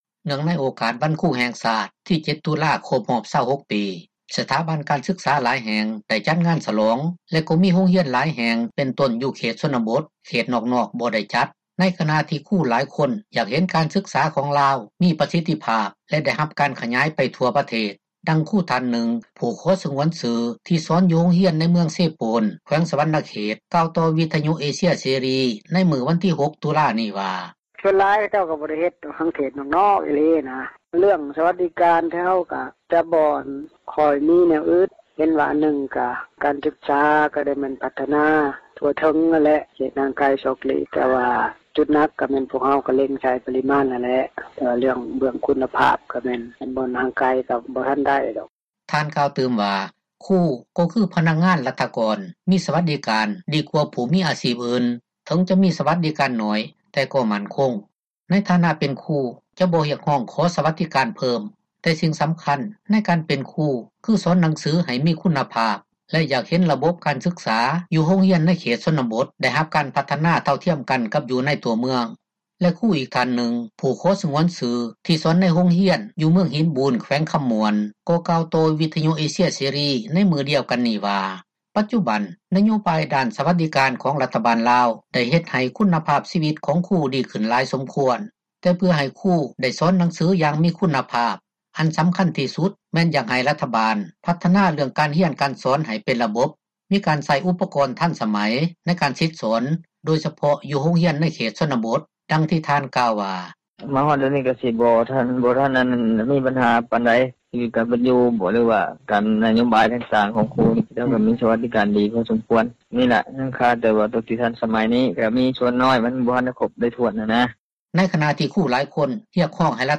ເນື່ອງໃນໂອກາດວັນຄຣູແຫ່ງຊາດ ທີ 7 ຕຸລາ ຄົບຮອບ 26 ປີ ສະຖາບັນການສຶກສາຫຼາຍແຫ່ງໄດ້ຈັດງານສລອງ ແລະກໍມີໂຮງຮຽນຫຼາຍ ແຫ່ງເປັນຕົ້ນຢູ່ເຂດ ຊົນນະບົດເຂດນອກໆ ບໍ່ໄດ້ຈັດ ໃນຂນະທີ່ ຄຣູຫລາຍຄົນ ຢາກເຫັນການສຶກສາຂອງລາວ ມີປະສິດທິພາບ ແລະ ໄດ້ຮັບການຂຍາຍໄປທົ່ວປະເທດ, ດັ່ງຄຣູທ່ານນຶ່ງ ຜູ້ຂໍສງວນຊື່ ທີ່ສອນຢູ່ໂຮງຮຽນໃນ ເມືອງເຊໂປນ ແຂວງສວັນນະເຂດ ກ່າວຕໍ່ວິທຍຸ ເອເຊັຍ ເສຣີ ໃນມື້ວັນທີ 6 ຕຸລາ ນີ້ວ່າ: